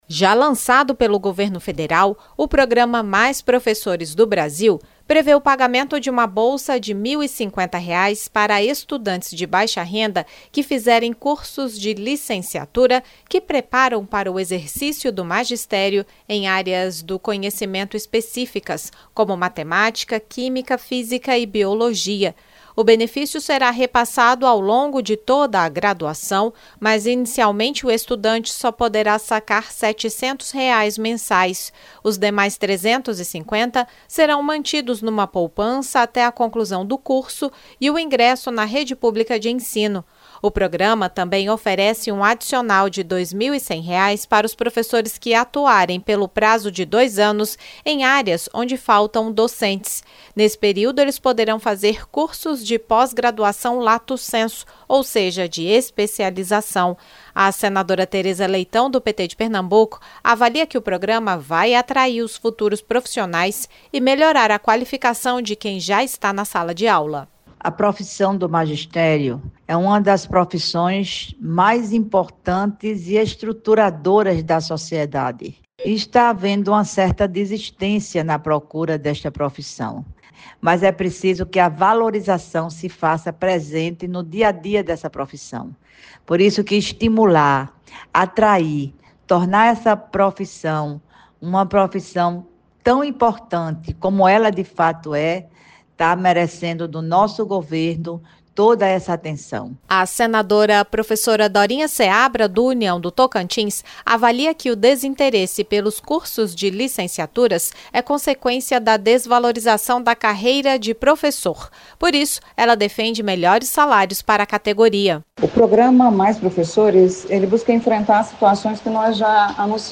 Entre outras medidas, o programa prevê bolsas para alunos que se matricularem em cursos de licenciatura — desde que atendidos uma série de critérios. As senadoras Teresa Leitão (PT-PE) e Professora Dorinha Seabra (União-TO) avaliam a iniciativa.